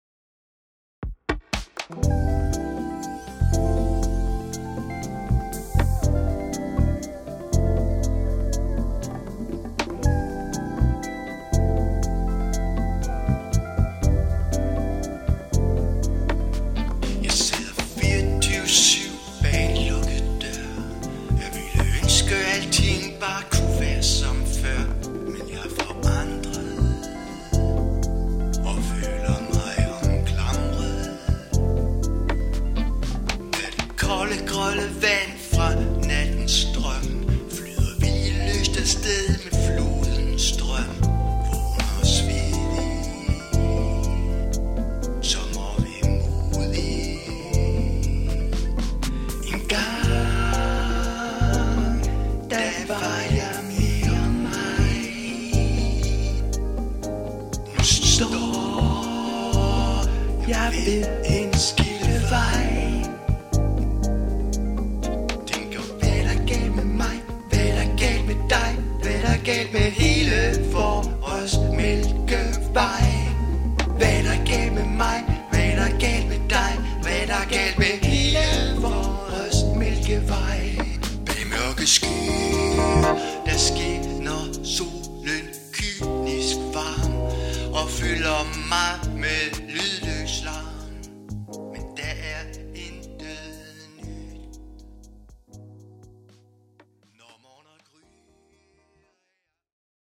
• Pop
• R'n'b
• Singer/songwriter
• Soul
Fusionspop med danske tekster